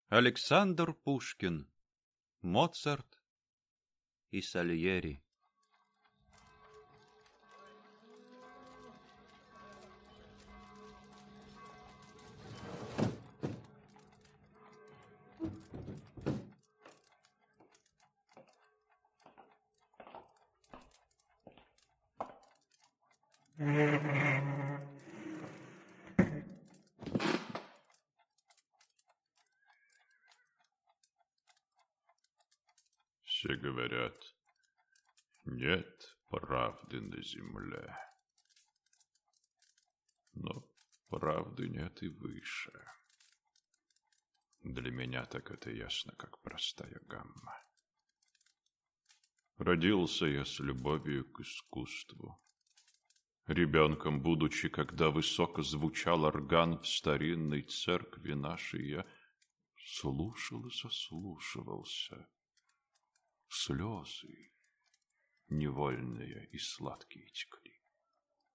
Аудиокнига Моцарт и Сальери | Библиотека аудиокниг